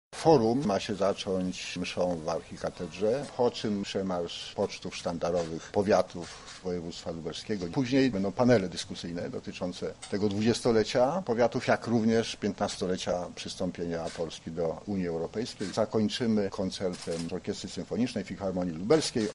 O tym jaki będzie jego przebieg mówi Zbigniew Antoń, starosta powiatu lubelskiego